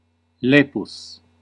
Ääntäminen
Ääntäminen France: IPA: [œ̃ ljɛvʁ] Tuntematon aksentti: IPA: /ljɛvʀ/ IPA: /ljɛvʁ/ Haettu sana löytyi näillä lähdekielillä: ranska Käännös Ääninäyte 1. lepus {m} Suku: m .